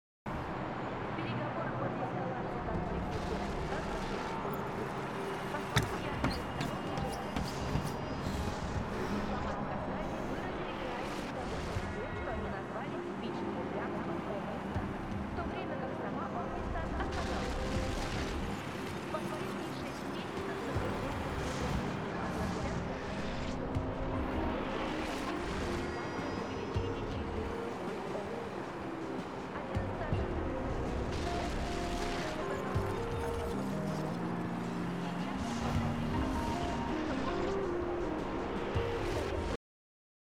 Звуки киберпанка
Звучание города будущего